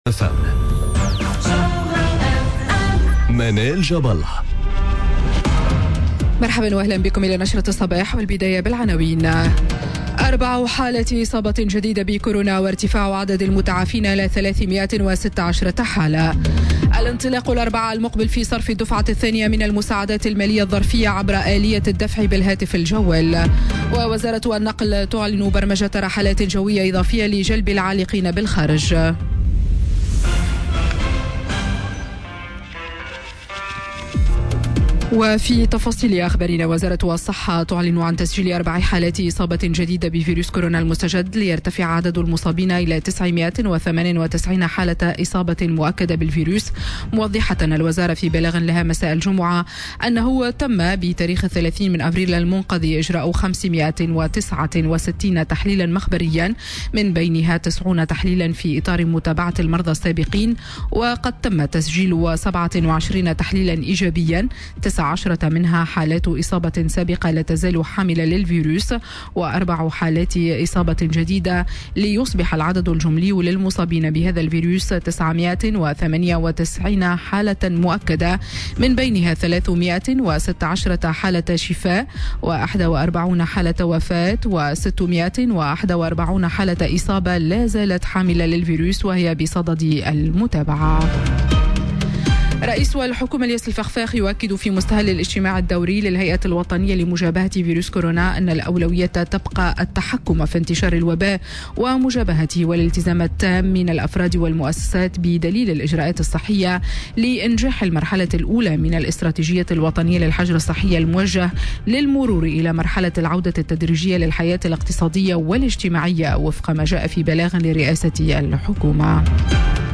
نشرة أخبار السابعة صباحا ليوم السبت 02 ماي 2020